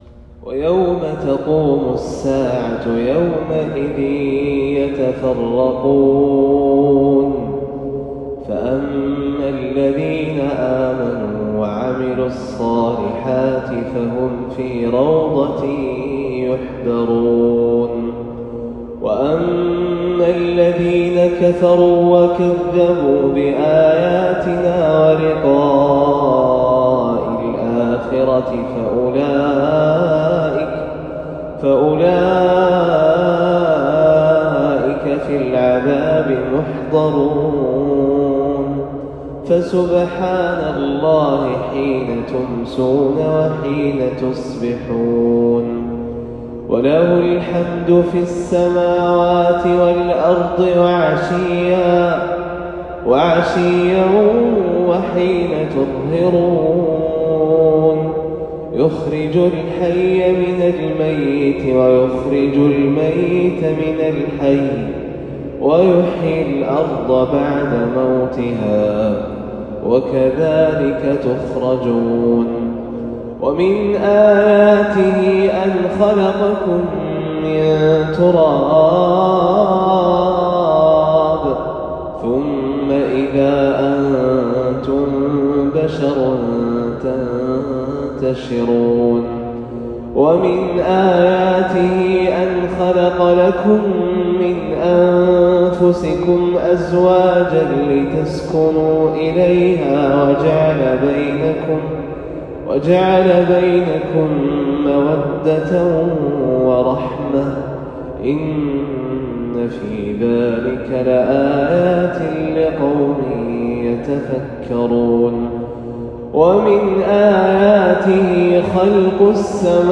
تلاوة بياتية فجرية خاشعة
تلاوة من سورة الروم للقارئ